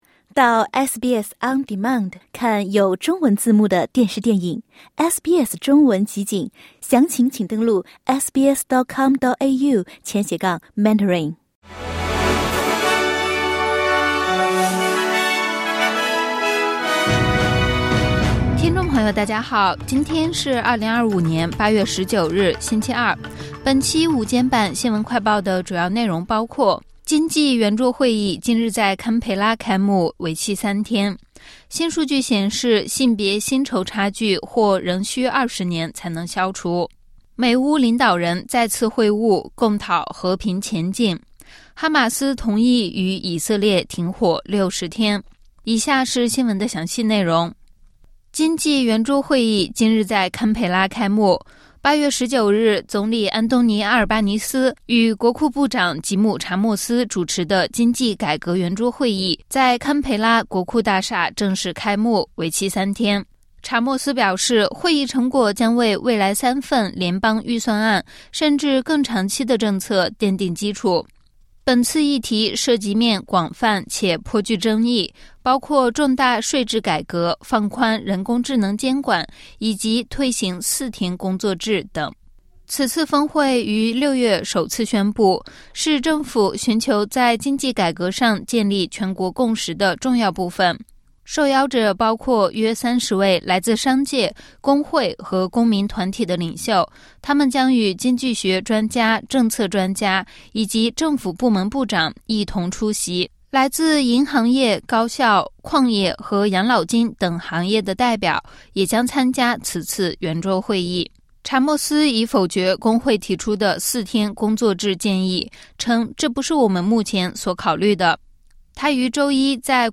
【SBS新闻快报】经济圆桌会议是什么？将讨论哪些话题？